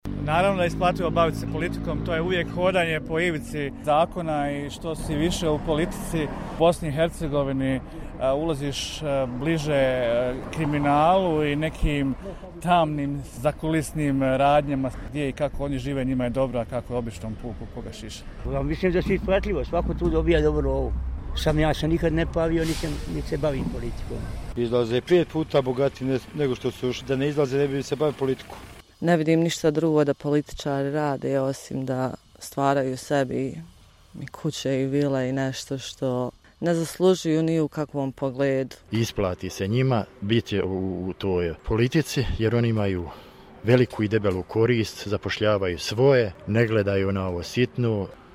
Stavovi građana